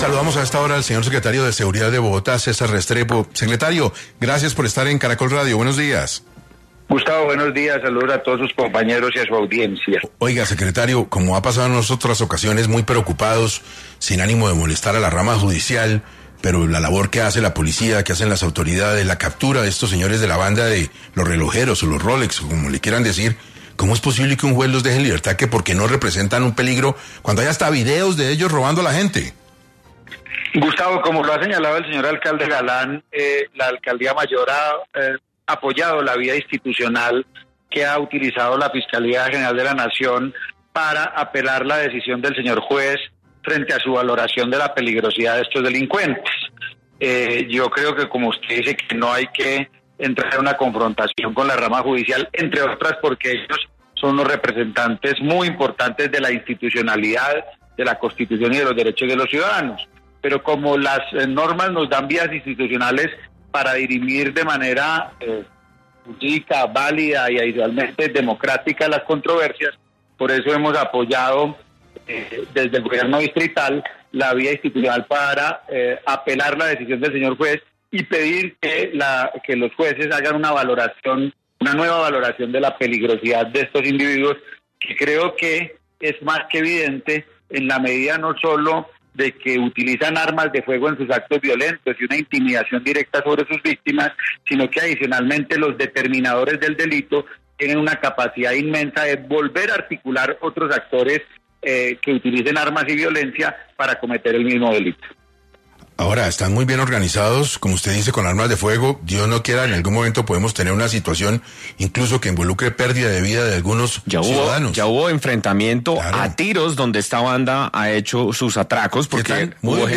En 6AM de Caracol Radio habló Cesar Restrepo, secretario de Seguridad de Bogotá, quien enfatizó que desde la alcaldía buscan que se garantice la seguridad en la ciudad frente a esta banda criminal que ataca con armas de fuego